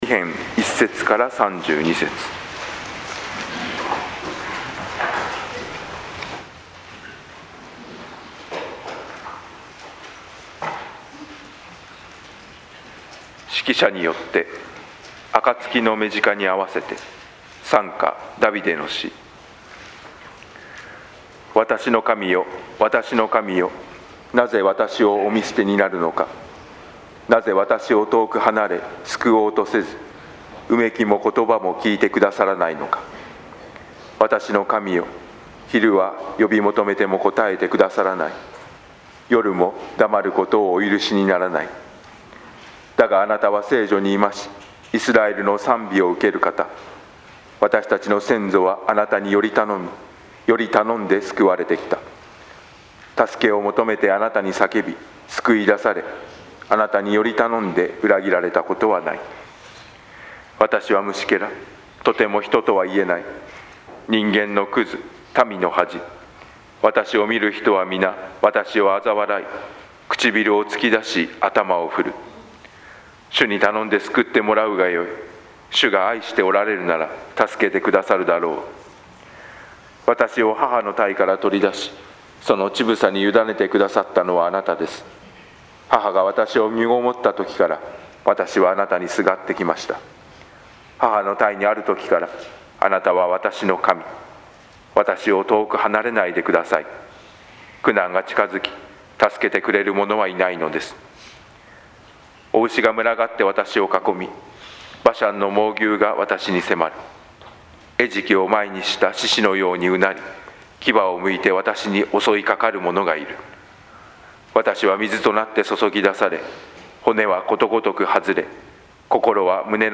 説教題「主イエスの死」 牧師